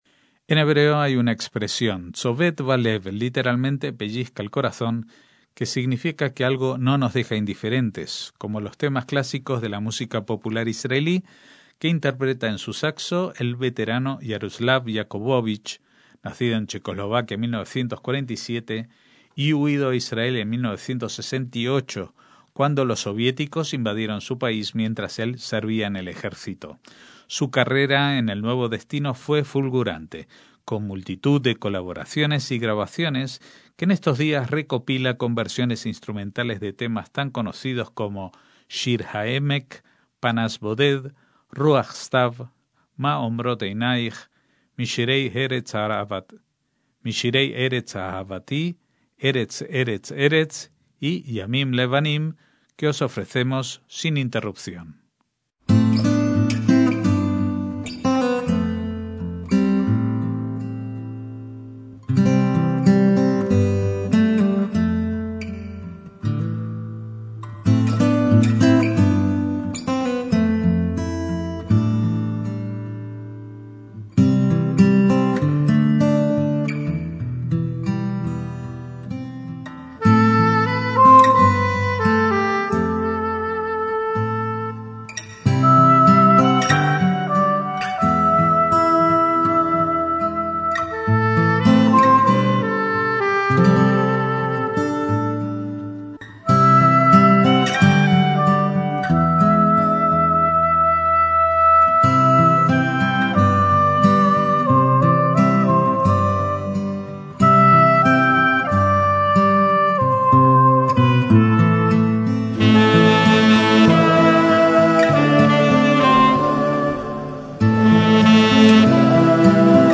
MÚSICA ISRAELÍ
saxo
versiones instrumentales